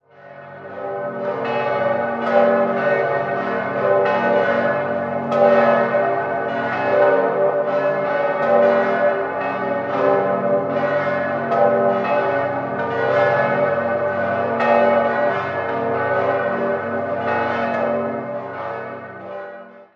5-stimmiges Hauptgeläute: g°-b°-d'-e'-fis'
Halberstadt_Dom_Hauptgelaeute.mp3